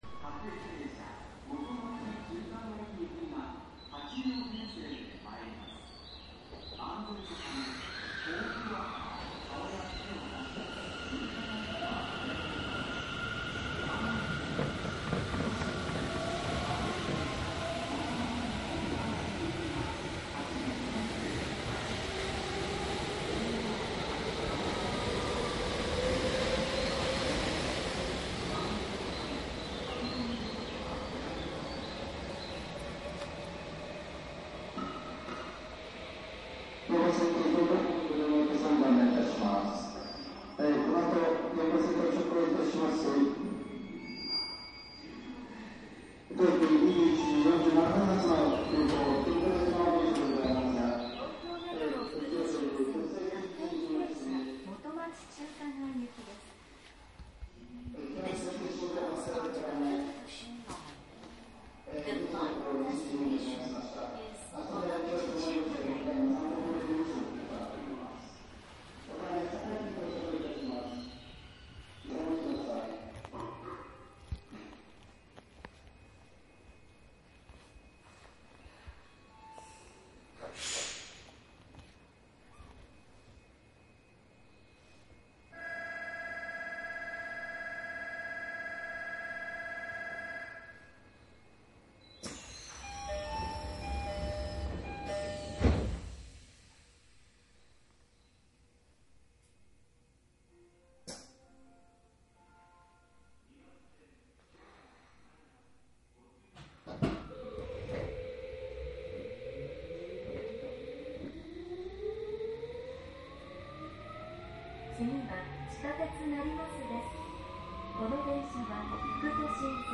♫東京メトロ17000系（８両編成）副都心線渋谷方面　走行音　CD
1７０００系８両編成、西武線・副都心線内の録音。
マスター音源はデジタル44.1kHz16ビット（マイクＥＣＭ959）で、これを編集ソフトでＣＤに焼いたものです。